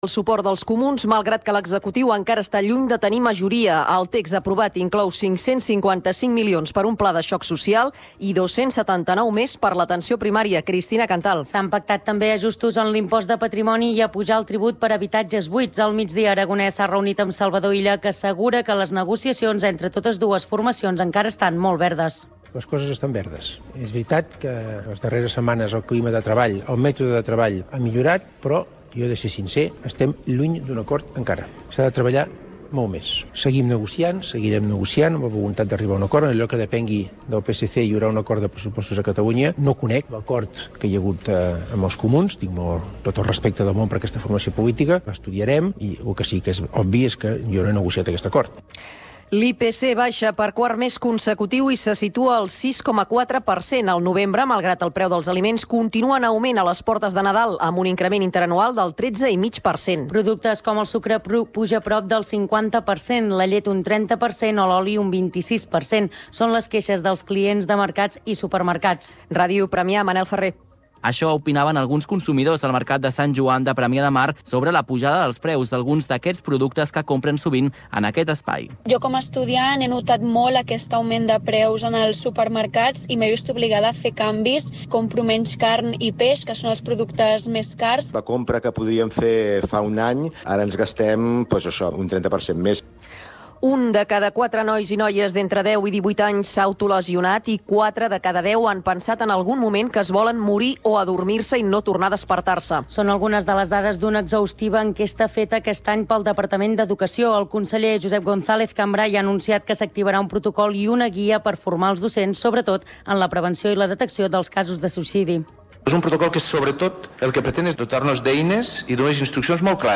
Informatiu en xarxa que fa difusió nacional dels fets locals i ofereix la visió local dels fets nacionals.